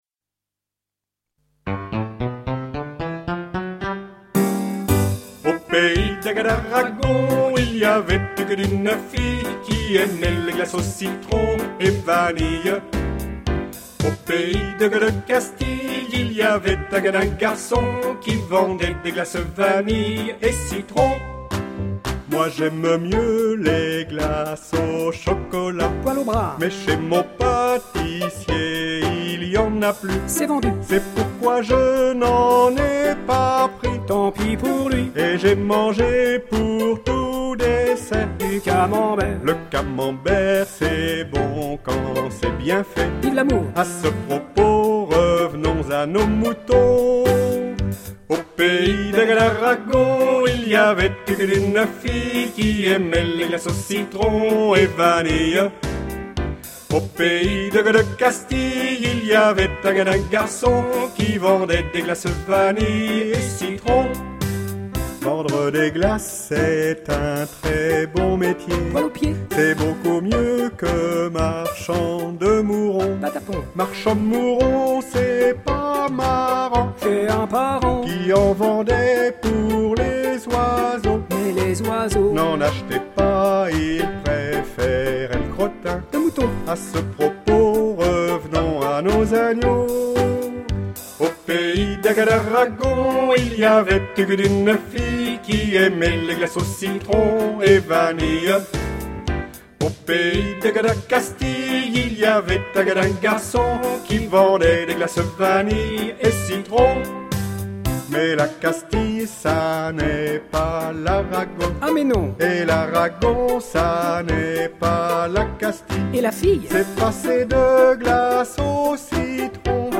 Version chantée :